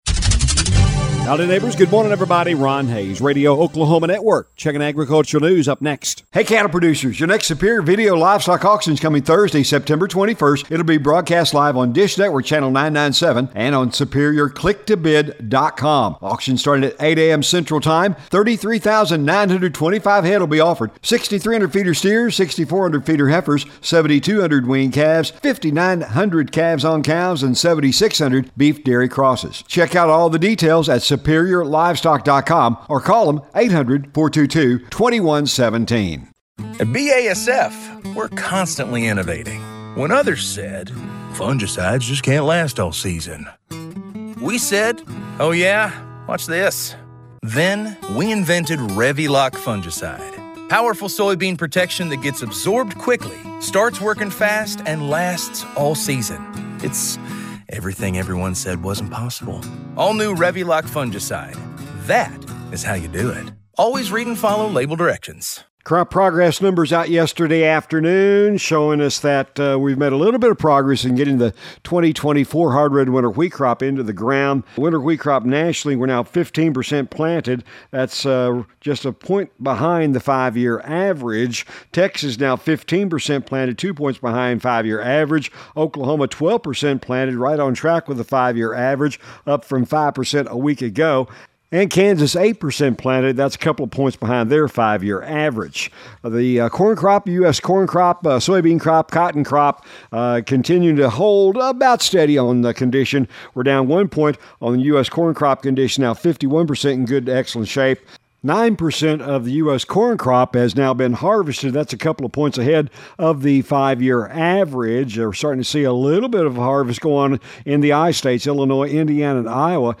if you missed this morning's Farm News